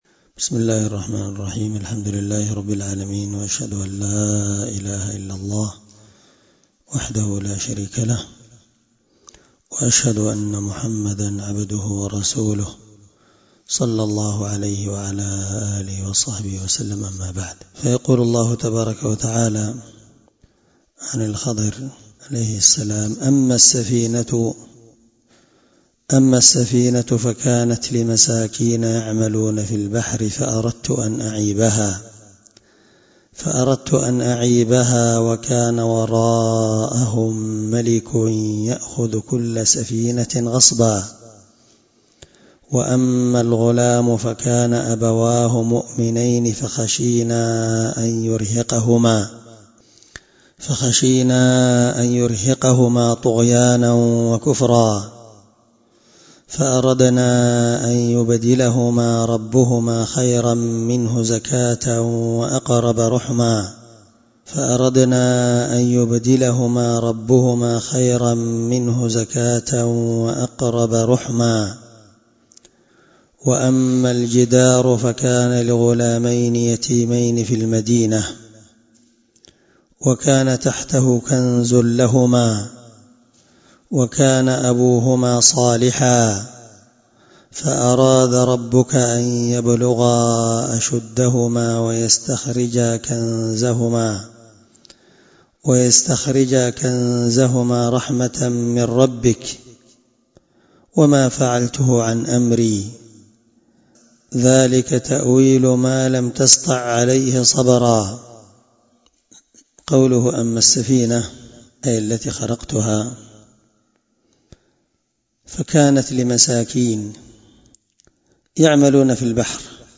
الدرس25 تفسير آية (79-82) من سورة الكهف